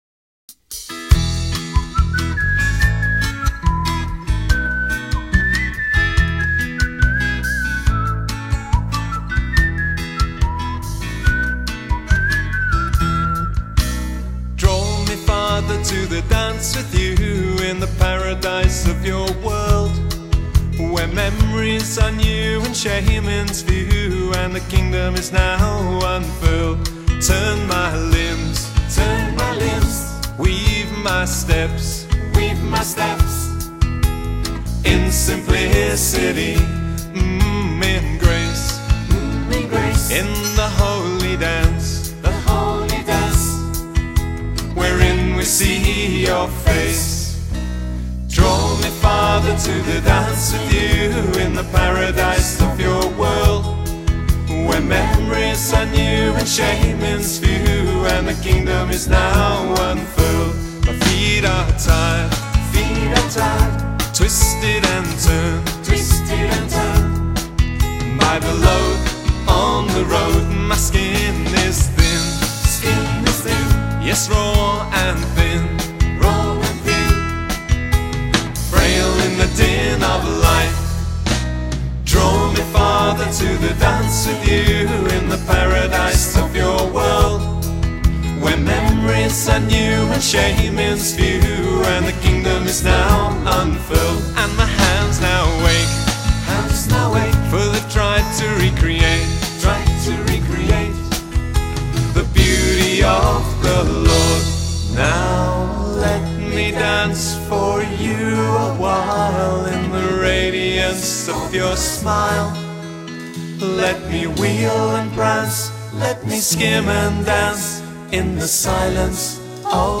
tender, lilting tune